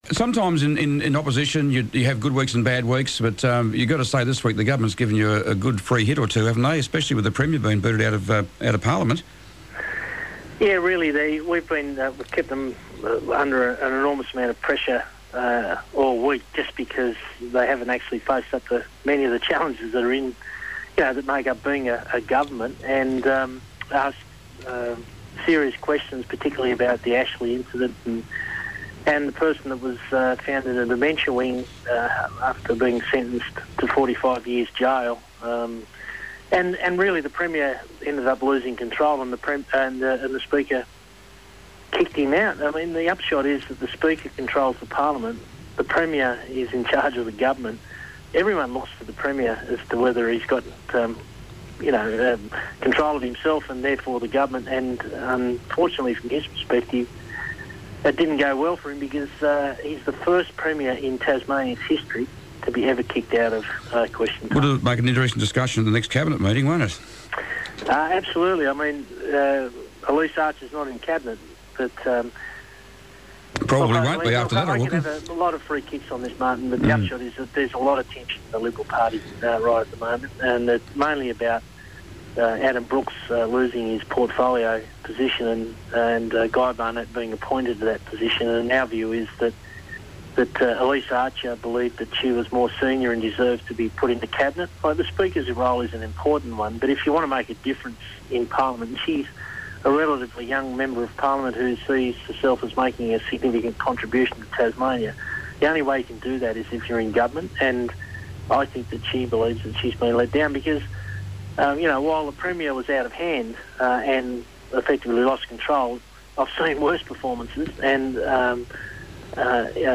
Tasmanian Opposition Leader Bryan Green stepped up onto the Pollies Perch today. Bryan spoke about the premier's historic suspension from Parlaiment, the Tasmanian jobs crisis, and the independent inquiry into the devastating June floods . . .